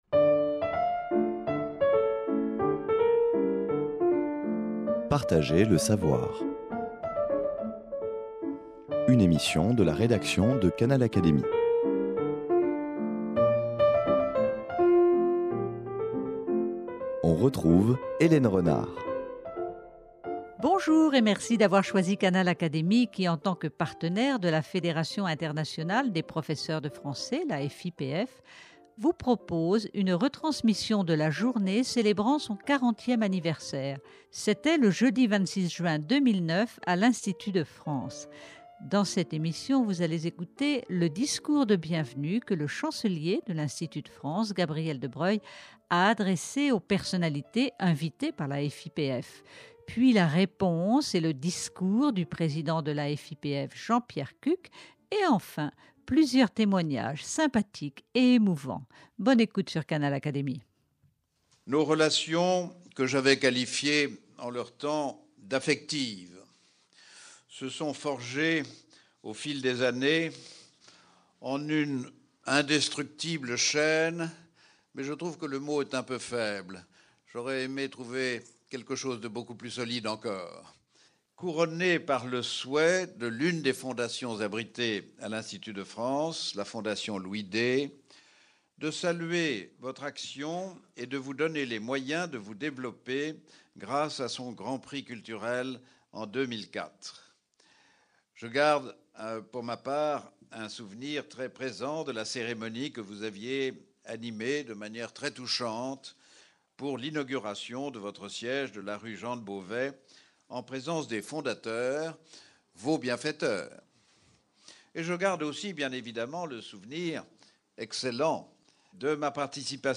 - Accueil par le Chancelier Gabriel de Broglie